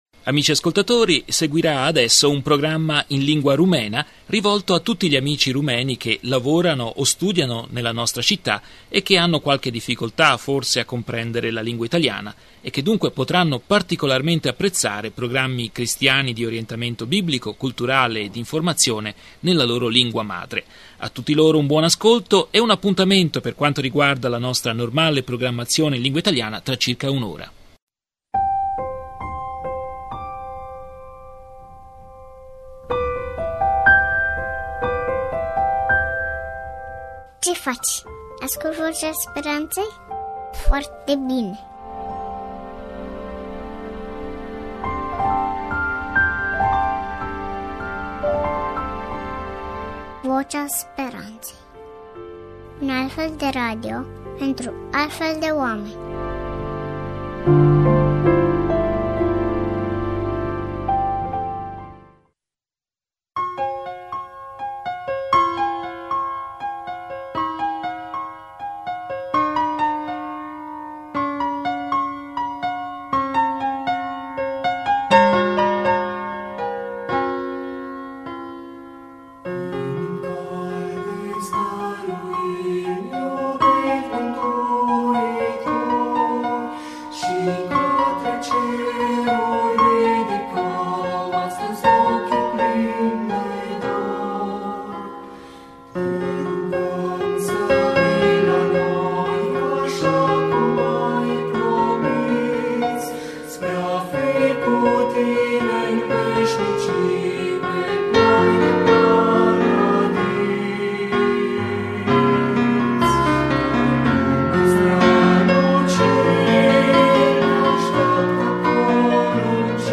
Programma in lingua rumena di informazione, cultura e riflessione cristiana a cura della redazione rumena di radio voce della speranza, trasmesso il 26 marzo 2007.